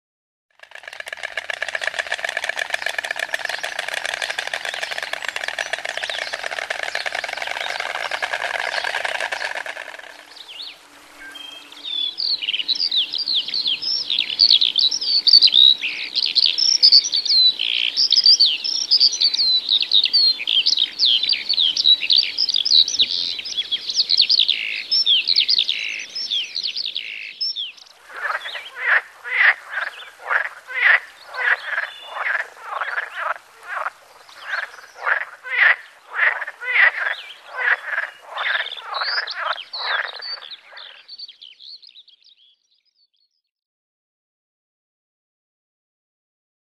1. Słuchanie odgłosów dochodzących z łąki.
Odgłosy -